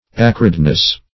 acridness - definition of acridness - synonyms, pronunciation, spelling from Free Dictionary
Acridity \A*crid"i*ty\, Acridness \Ac"rid*ness\n.